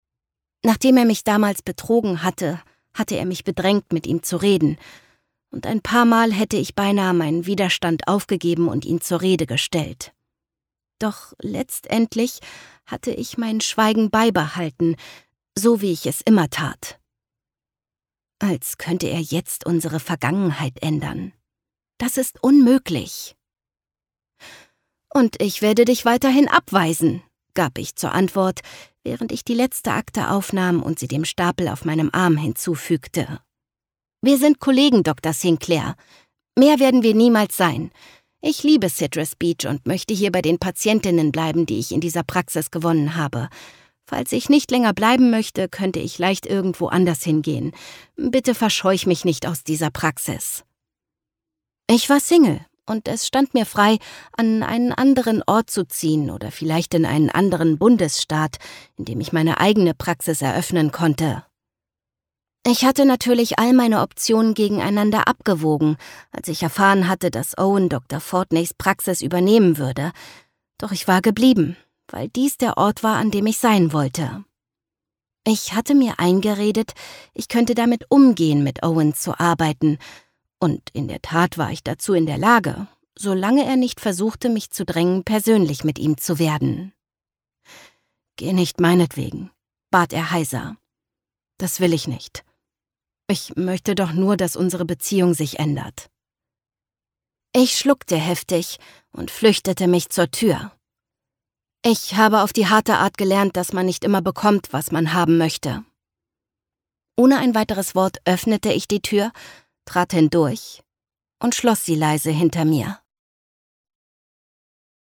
Infos zum Hörbuch